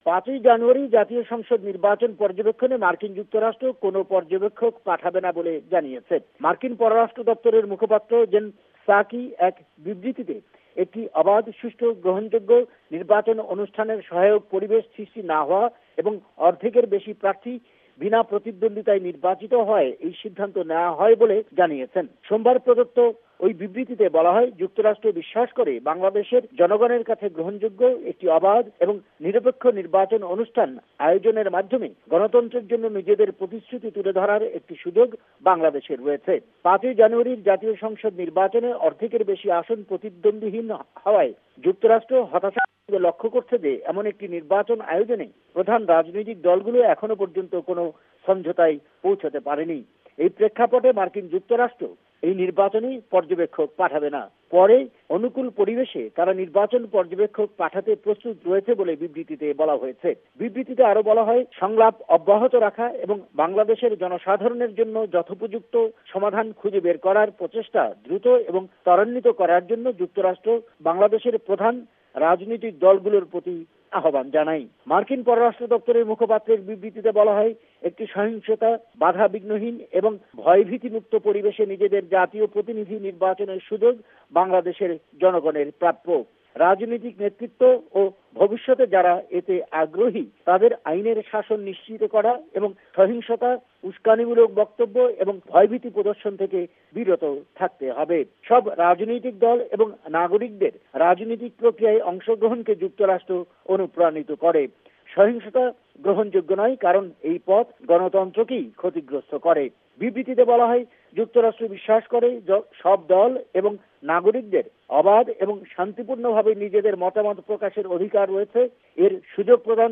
bangla elections report